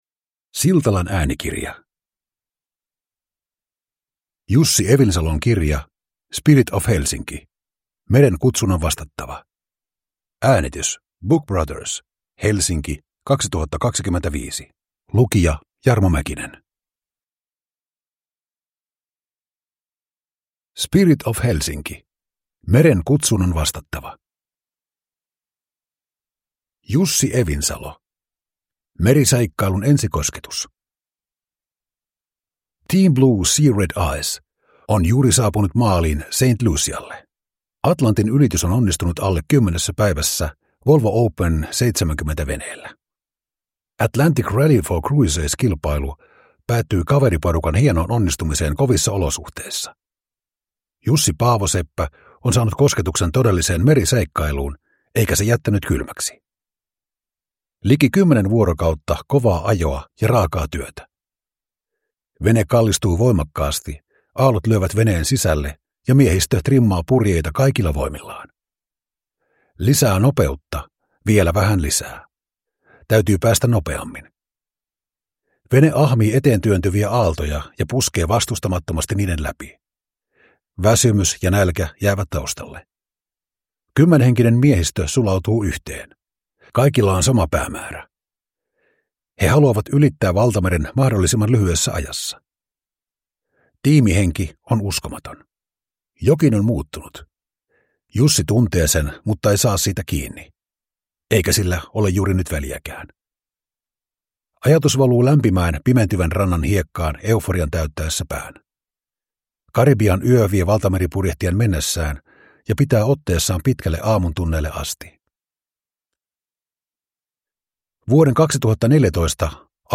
Spirit of Helsinki – Ljudbok